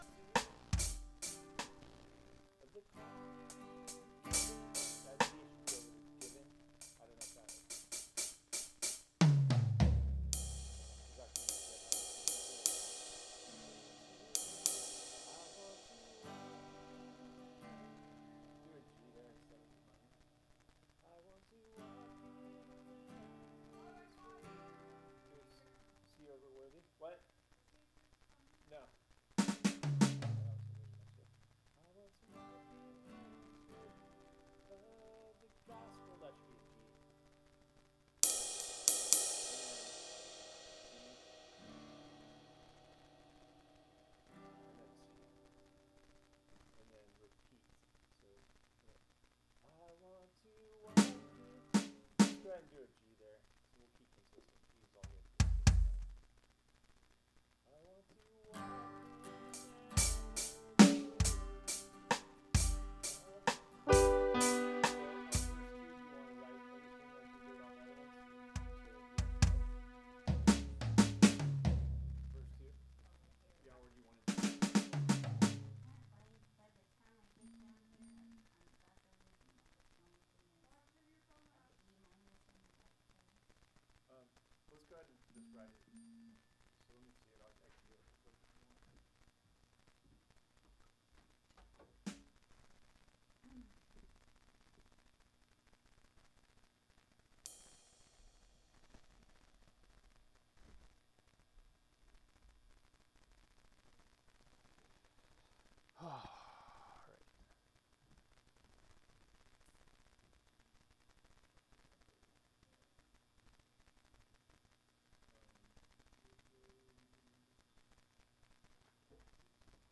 Sermons by Calvary Chapel Lowcountry